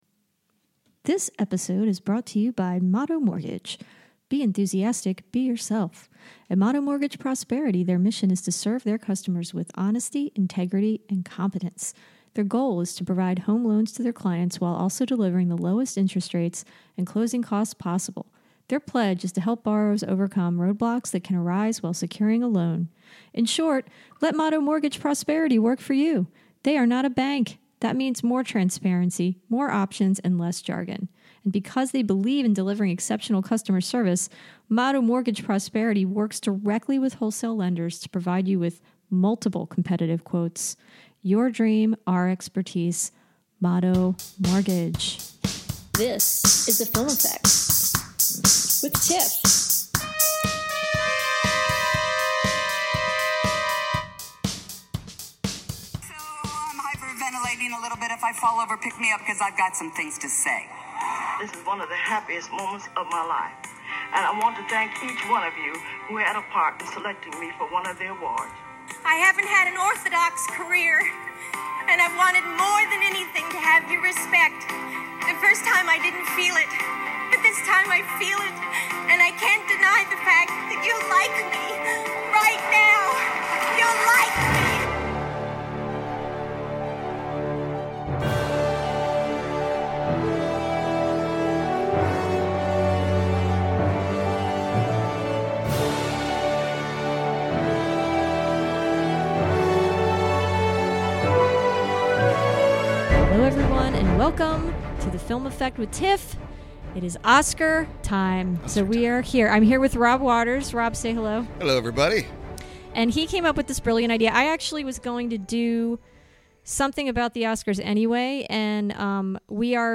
Join us for an entertaining chat about all things Oscars!